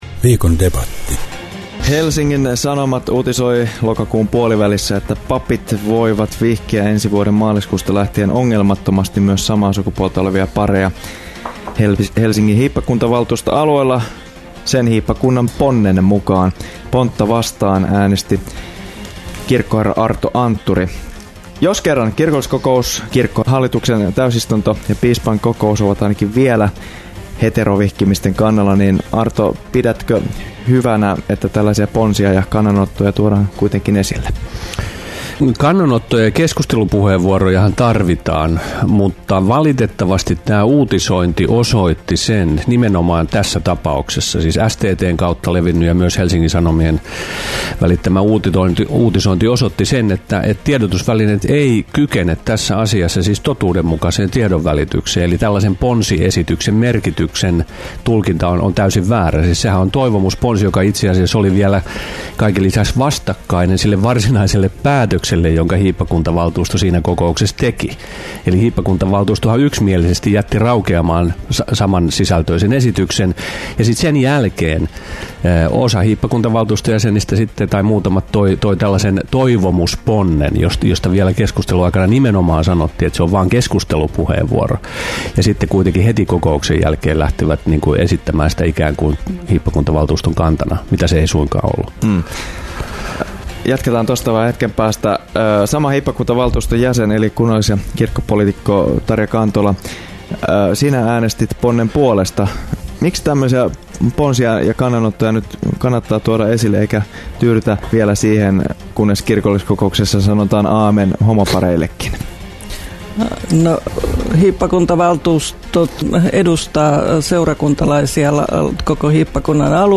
Näihin teemoihin haettiin jo vauhtia Radio Dein Viikon debatissa.